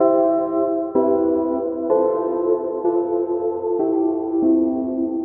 悲伤的循环
Tag: 95 bpm Chill Out Loops Synth Loops 903.44 KB wav Key : Unknown FL Studio